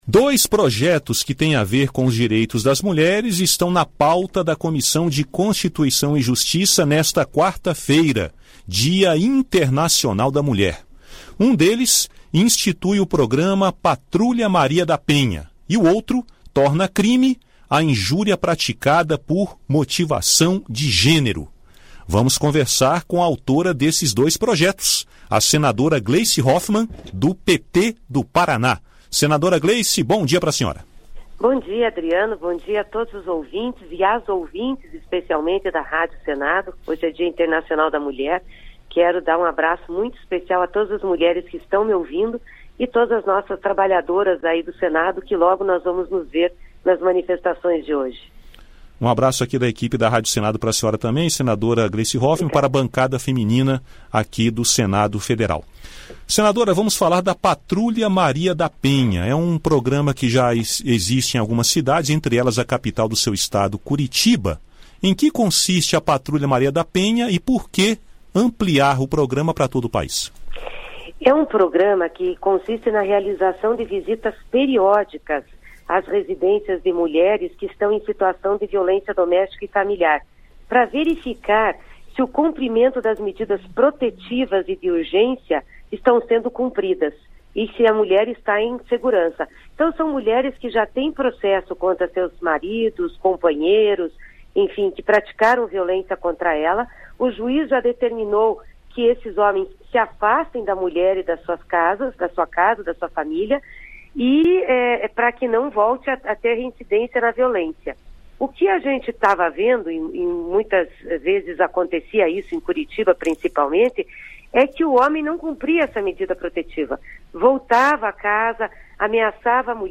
Neste Dia Internacional da Mulher, celebrado nesta quarta (8), o Plenário do Senado e a Comissão de Constituição, Justiça e Cidadania (CCJ) só deverão votar projetos relacionados aos direitos das mulheres. Foi o que afirmou a senadora Gleisi Hoffmann (PT-PR), em entrevista à Rádio Senado.